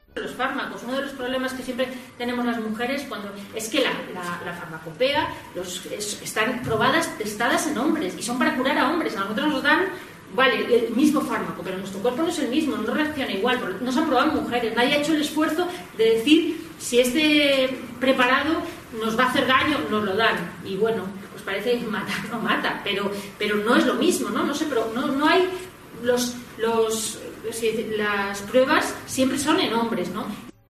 Palabras de Beatriz Gimeno